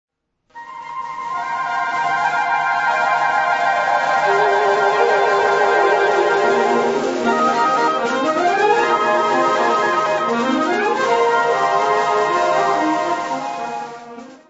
Subcategorie Suite
Bezetting Ha (harmonieorkest)